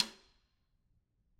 Snare2-taps_v4_rr1_Sum.wav